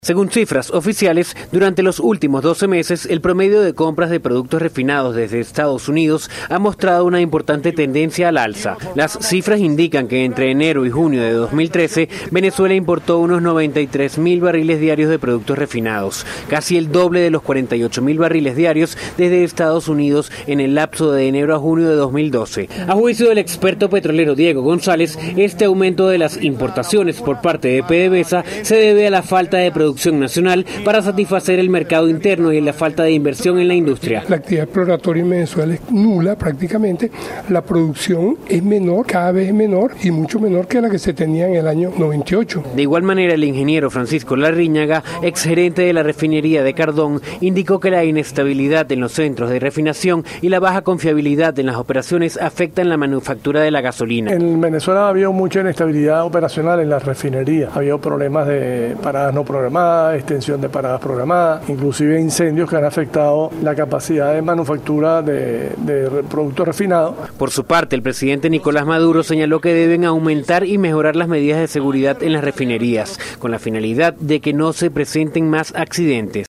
Desde Caracas